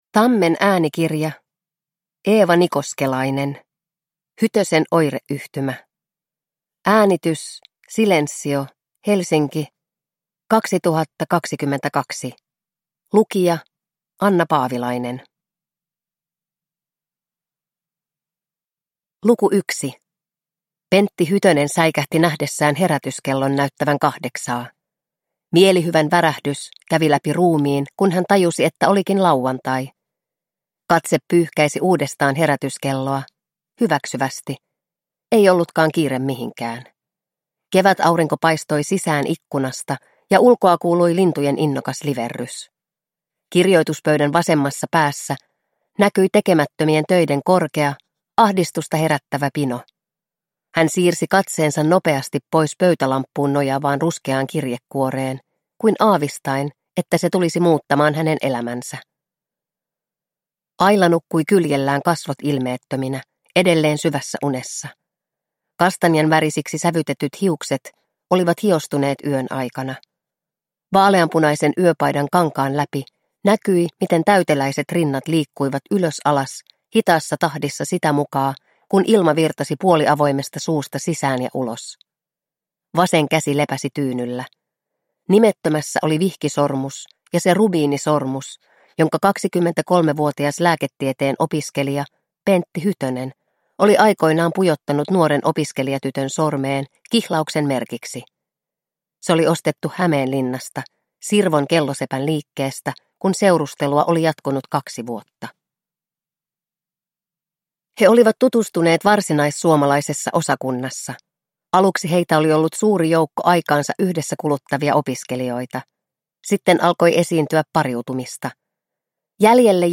Hytösen oireyhtymä – Ljudbok – Laddas ner